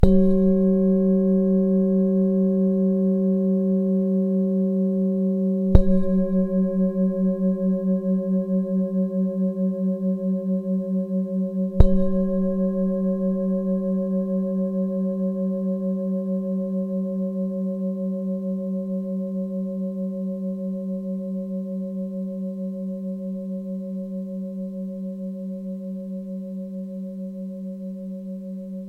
Zdobená tibetská mísa F#3 24cm
Nahrávka mísy úderovou paličkou:
Jde o ručně tepanou tibetskou zpívající mísu dovezenou z Nepálu.